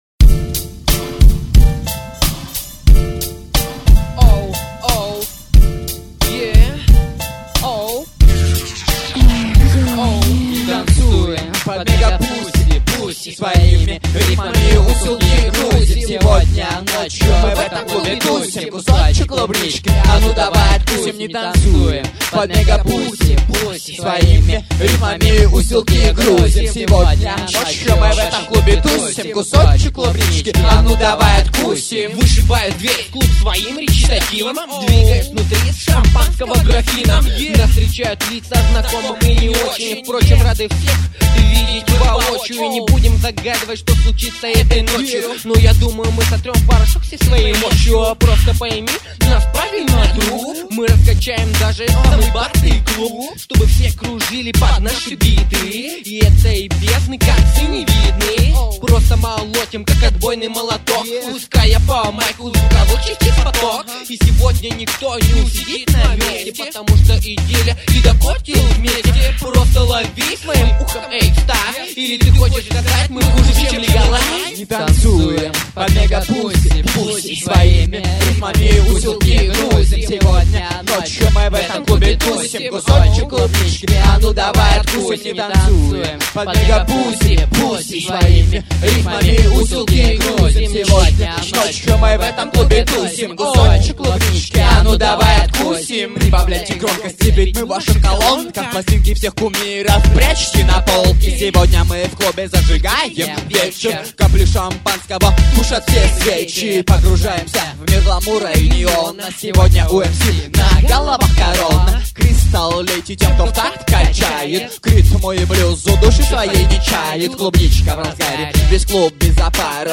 • Остальное:, 2007 Рэп